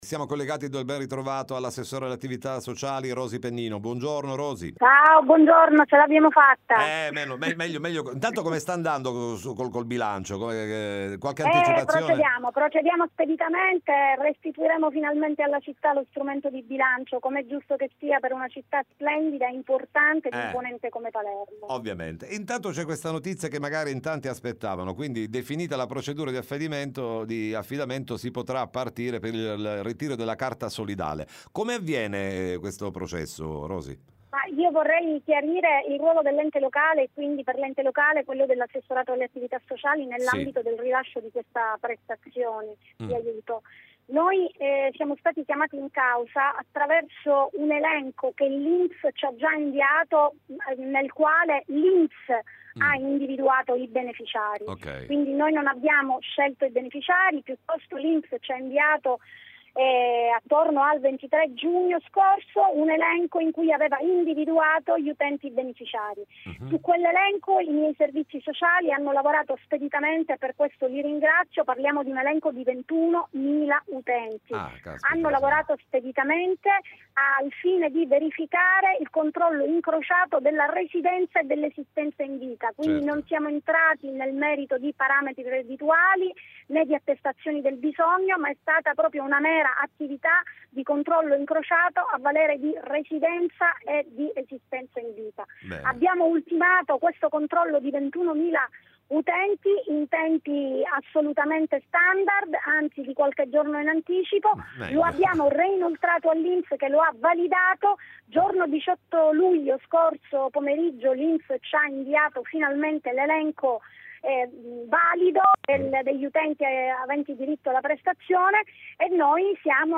Arriva la carta solidale a Palermo, ne parliamo con l’ass. alle attività sociali Rosi Pennino
Arriva la carta solidale a Palermo Interviste Time Magazine 21/07/2023 12:00:00 AM / Time Magazine Condividi: Arriva la carta solidale a Palermo, ne parliamo con l’ass. alle attività sociali Rosi Pennino